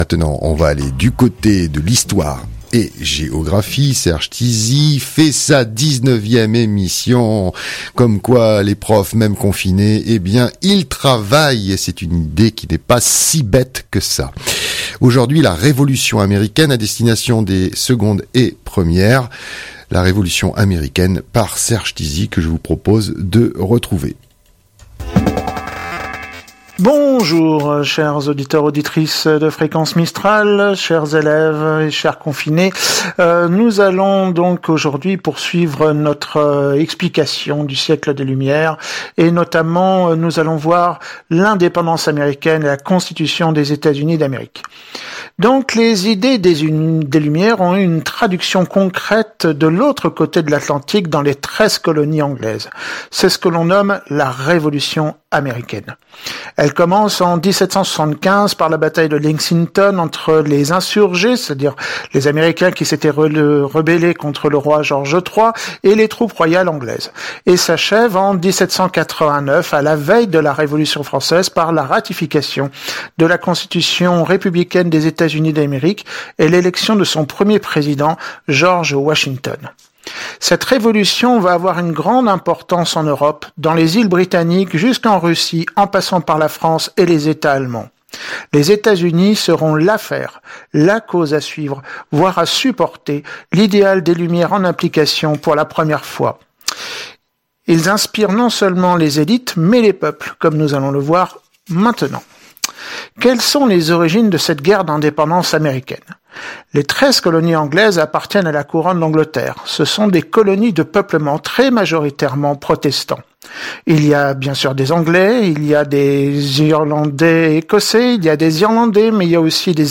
Cours radiophonique histoire géographie de terminale
enregistré chez lui